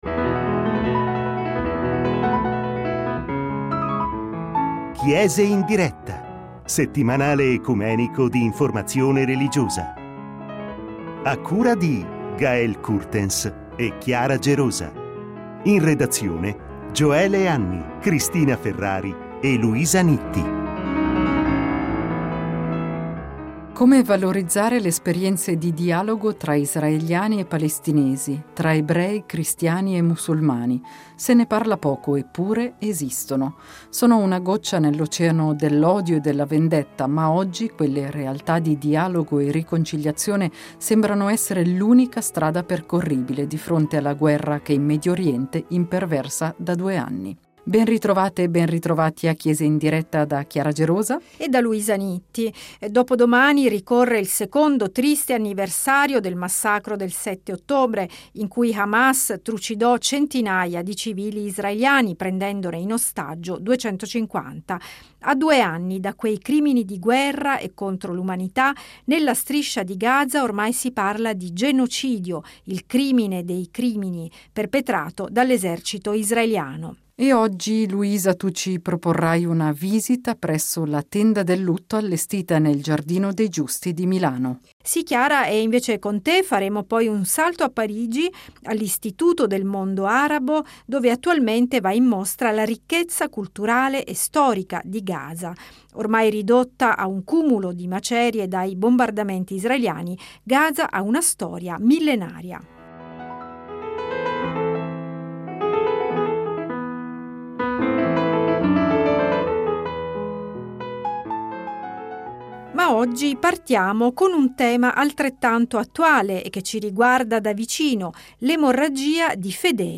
La sfida - raccontano due ospiti - è osare nuovi cammini, ascoltare, discernere, ricominciare insieme.